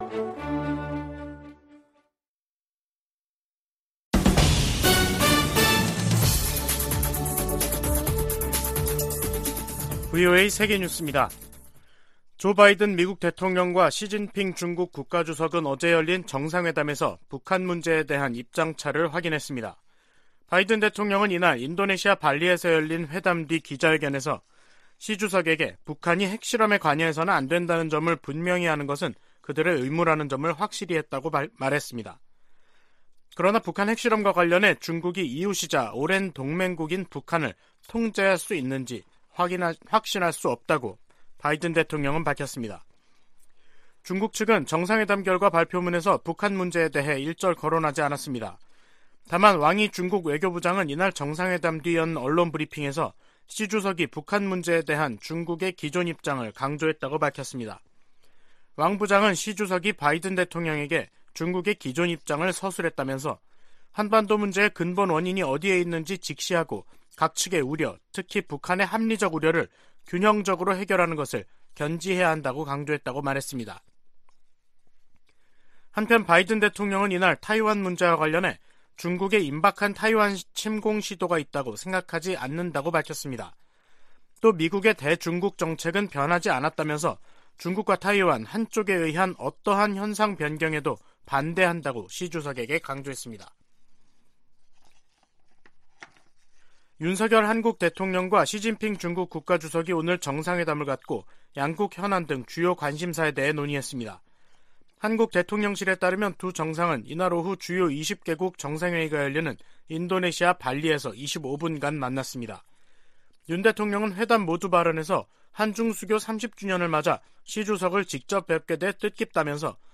VOA 한국어 간판 뉴스 프로그램 '뉴스 투데이', 2022년 11월 15일 2부 방송입니다. 조 바이든 미국 대통령은 인도네시아에서 열린 미중 정상회담에서 시진핑 국가주석에게 북한이 핵실험 등에 나서면 추가 방위 조치를 취할 것이라고 말했다고 밝혔습니다. 미국과 중국의 정상회담에서 북한 문제 해법에 대한 견해차가 확인되면서 한반도를 둘러싸고 높아진 긴장이 지속될 전망입니다.